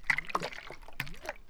slosh3.wav